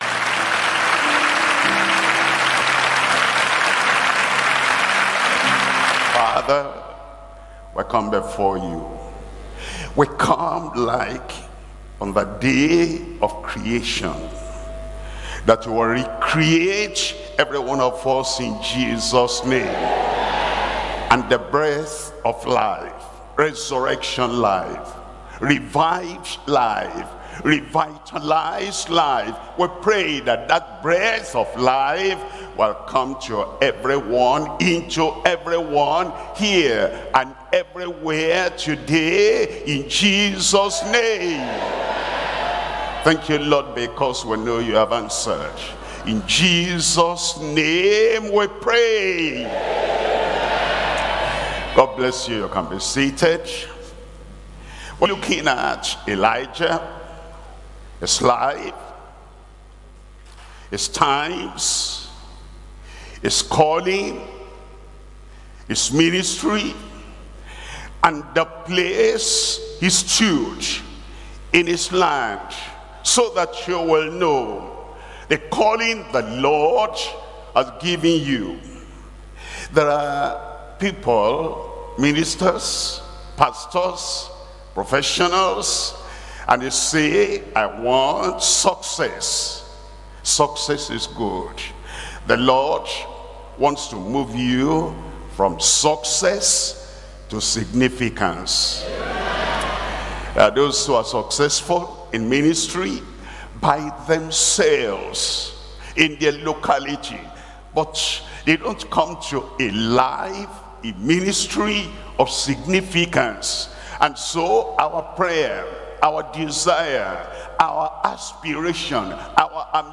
Sermons – Deeper Christian Life Ministry, United Kingdom